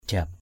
/ʥa:p/